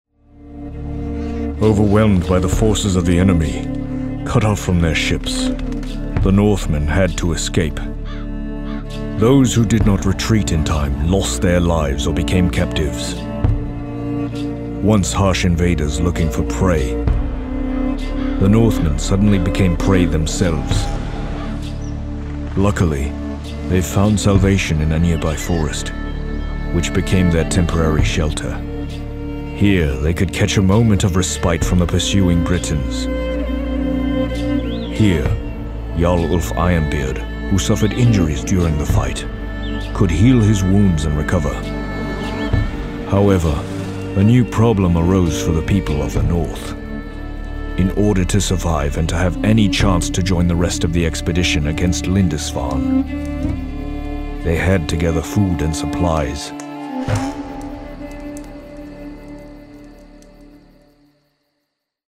Demo
Has Own Studio
british english
comedic